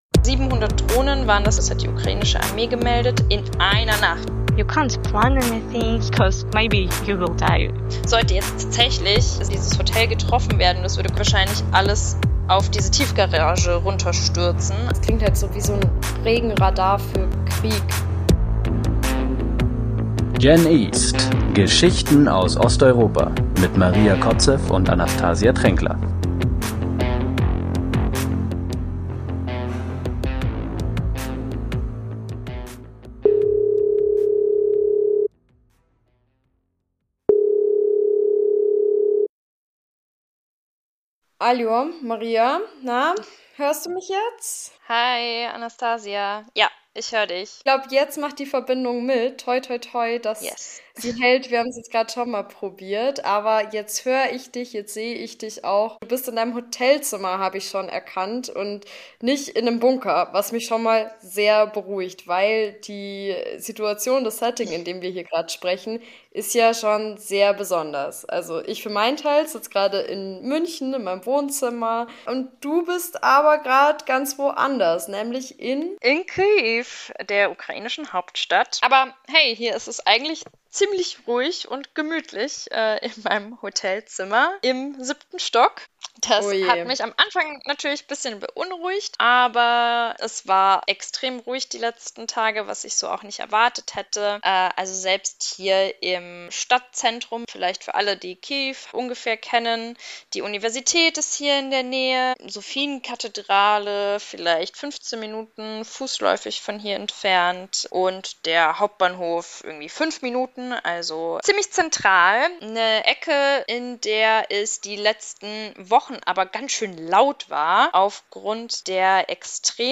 Beschreibung vor 8 Monaten [Hinweis: Diese Folge haben wir in der Nacht vom 17. auf den 18. Juli 2025 aufgenommen.]